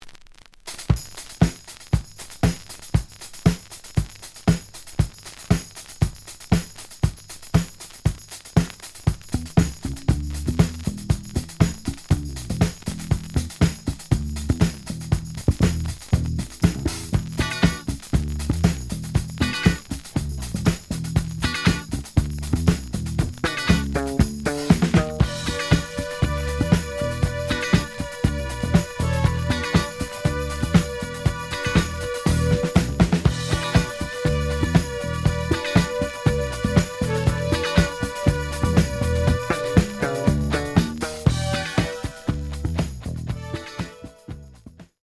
The audio sample is recorded from the actual item.
●Genre: Disco
Looks good, but slight noise on both sides.)